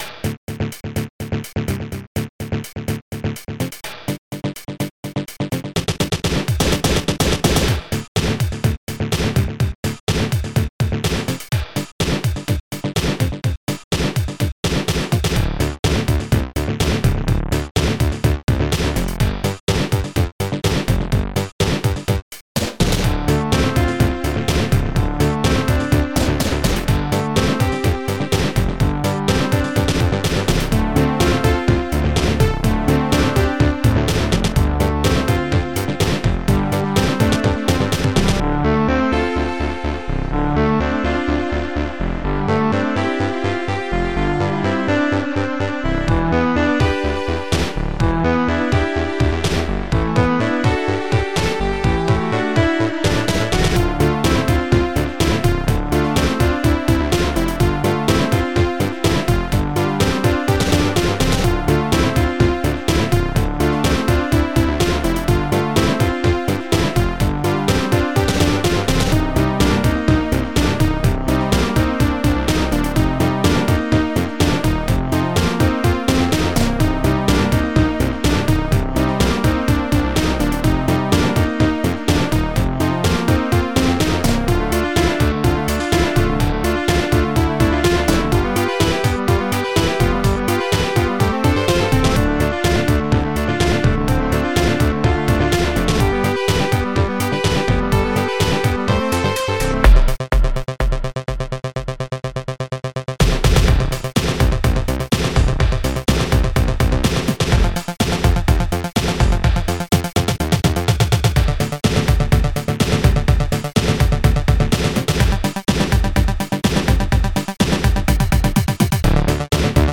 Oktalyzer Module